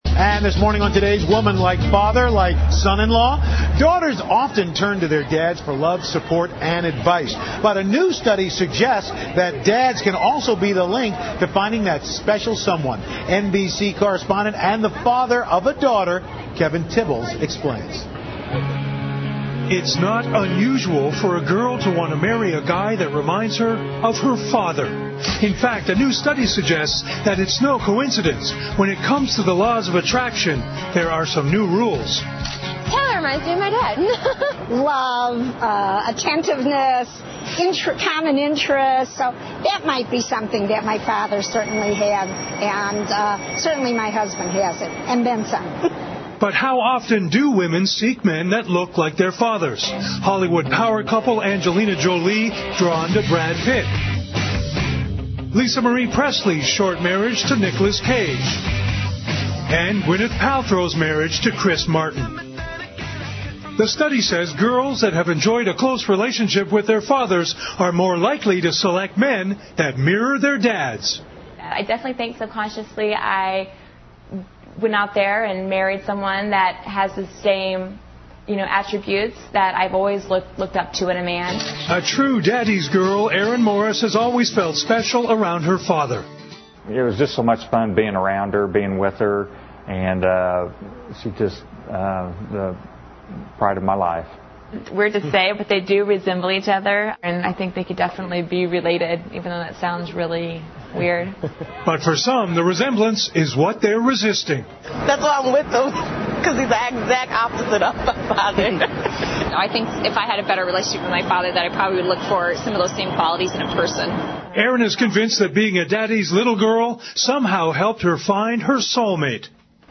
访谈录 Interview 2007-07-05&07-07, 解开女人们的恋父情结 听力文件下载—在线英语听力室